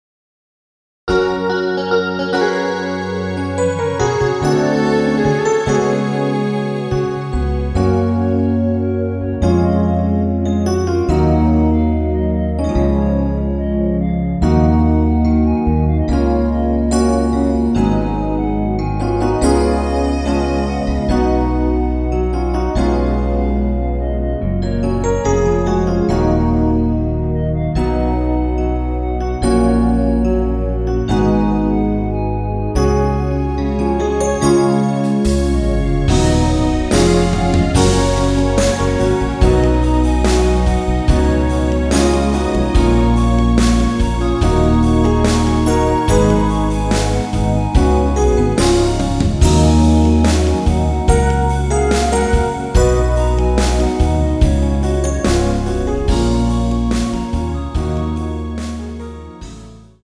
페이드 아웃곡이라 라이브하기좋게 엔딩을 만들어 놓았습니다.
원키 멜로디 포함된 MR입니다.
F#
앞부분30초, 뒷부분30초씩 편집해서 올려 드리고 있습니다.
중간에 음이 끈어지고 다시 나오는 이유는